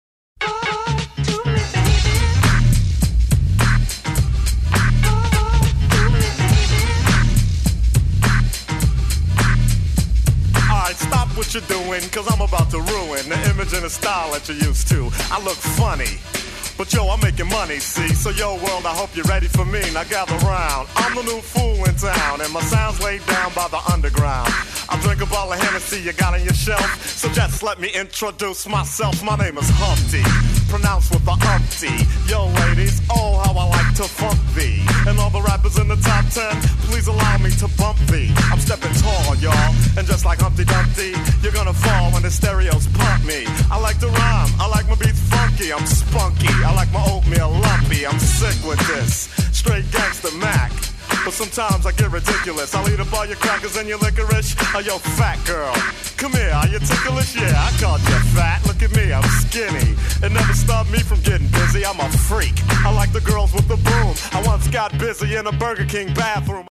sehr außergewöhnliche, rundum rollende Bassline